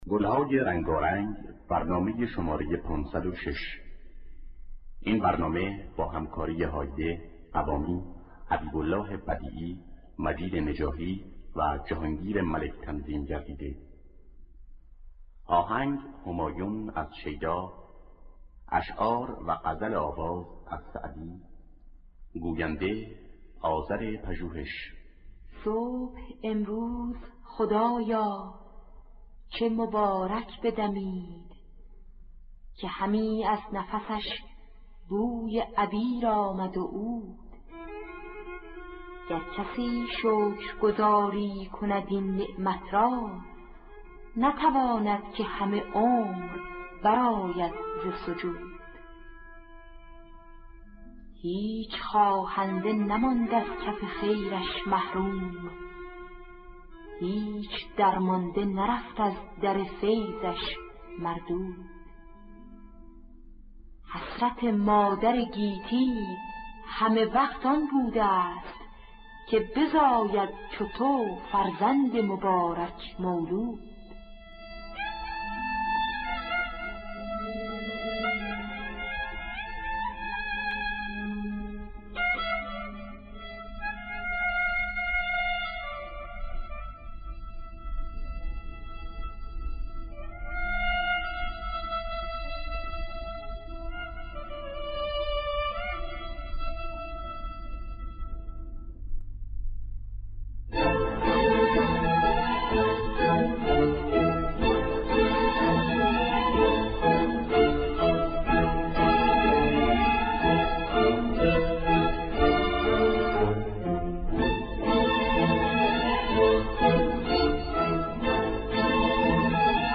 در دستگاه همایون